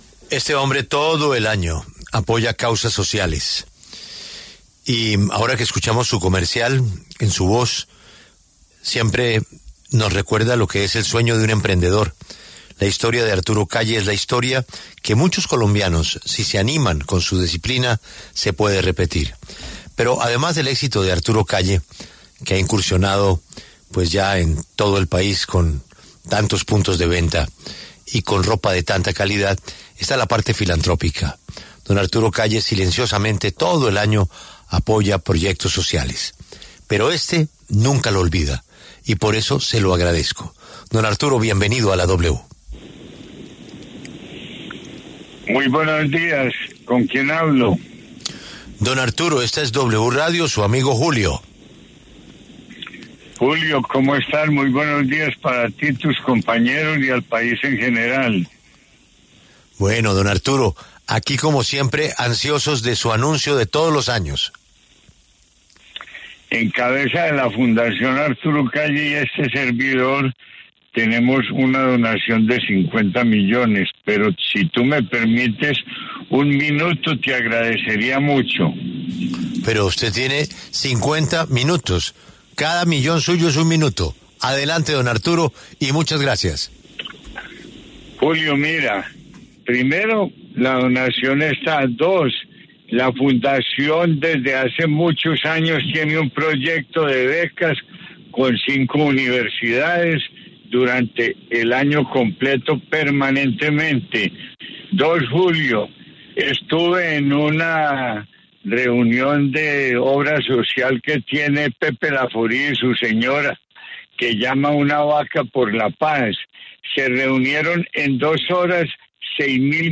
Este miércoles, 3 de diciembre, en los micrófonos de La W, con Julio Sánchez Cristo, habló Arturo Calle, fundador de la marca homónima, quien anunció que la Fundación Arturo Calle se suma a la campaña de Vamos Pa’ Lante con una donación de 50 millones de pesos.